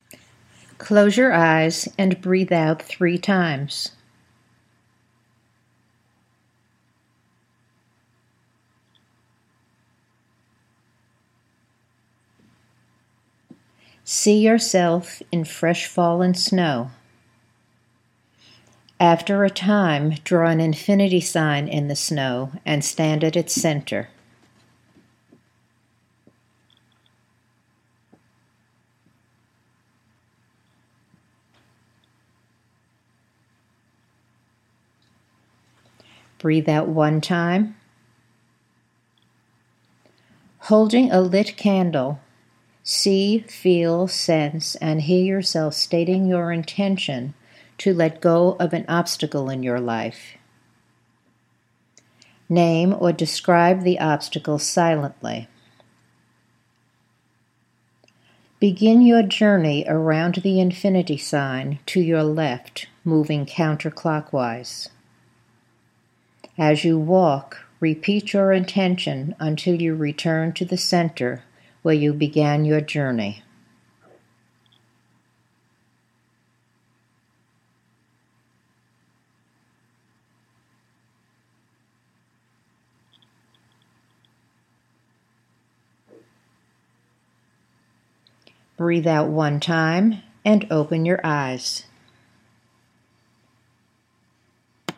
The exercise is not completed until I say Breathe out one time and open your eyes, and you hear the tone.